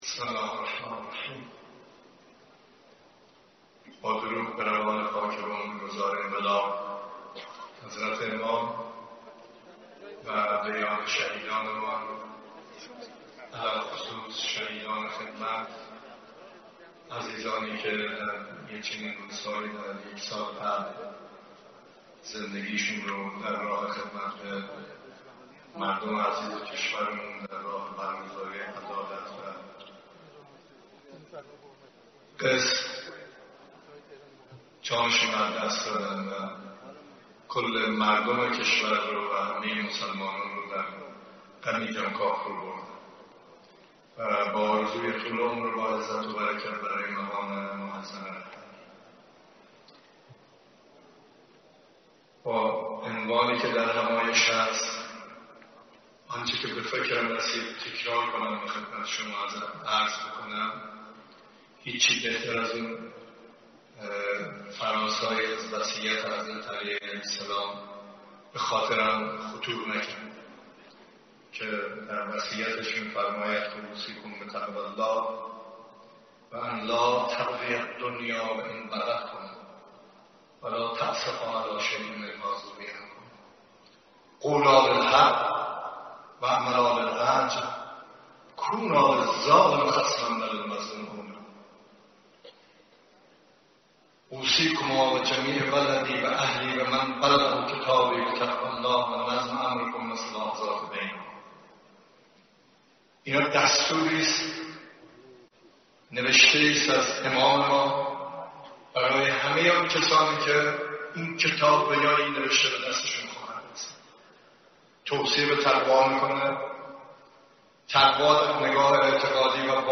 سخنان رئیس جمهور در همایش دیپلماسی مقاومت و گرامیداشت شهدای خدمت